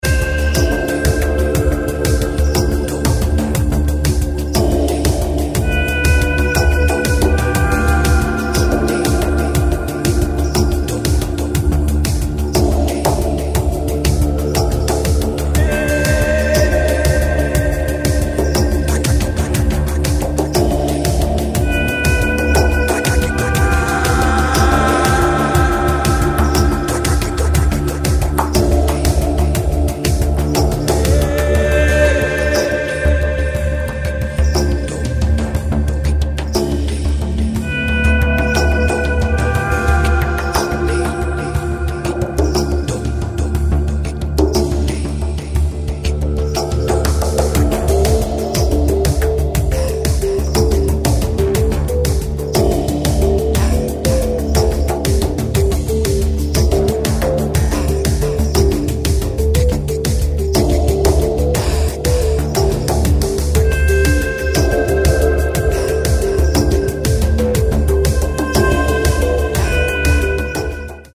IDM/Electronica, Trance